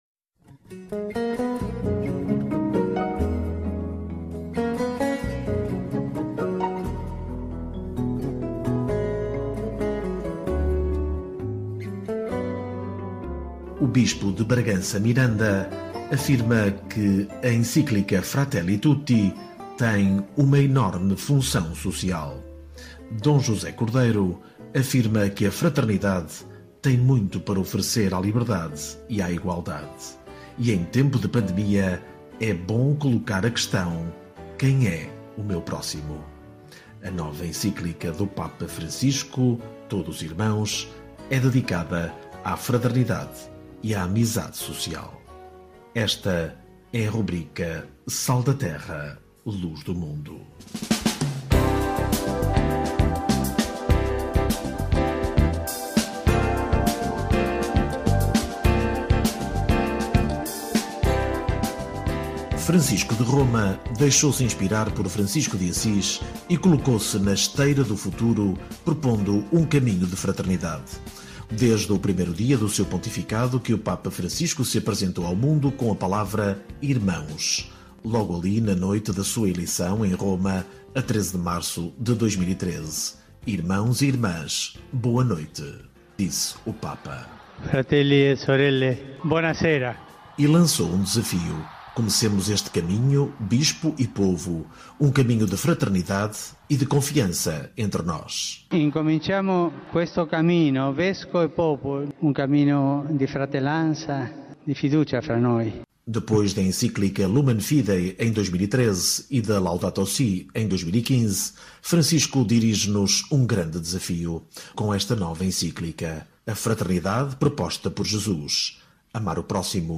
D. José Cordeiro, bispo de Bragança-Miranda, comentando a Encíclica do Papa Francisco “Fratelli tutti”, “Todos irmãos”. Uma entrevista ao Vatican News realizada com a colaboração do Secretariado Diocesano para a Comunicação Social da diocese de Bragança-Miranda.